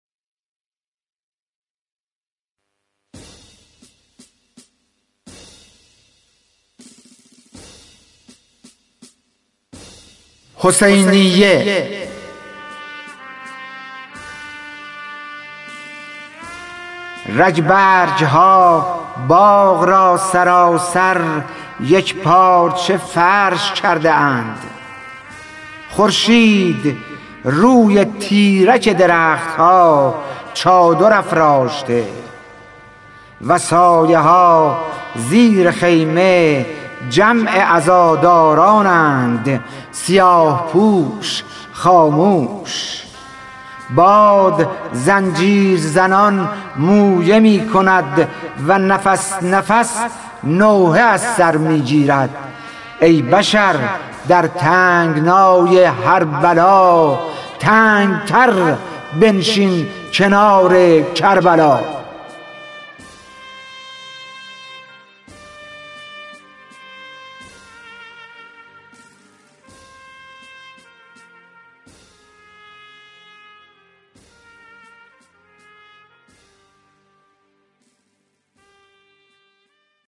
خوانش شعر سپید عاشورایی / ۶